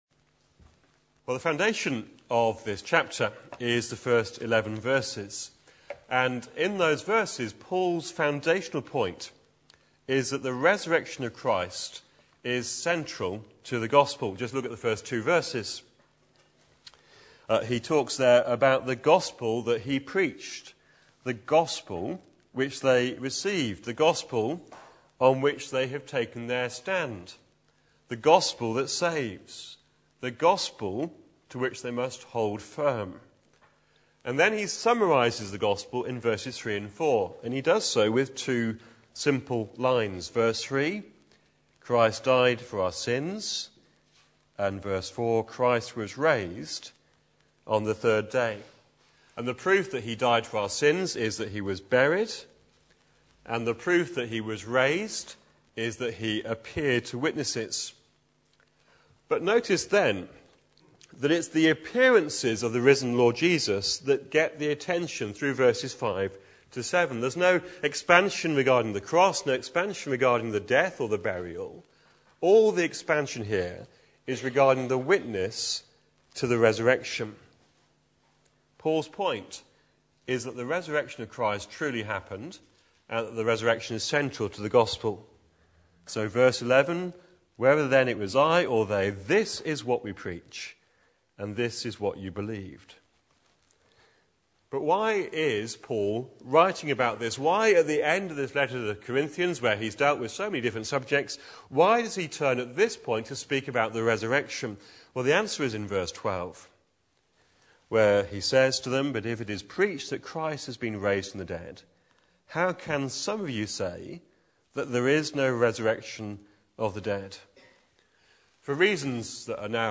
Back to Sermons The resurrection… so what?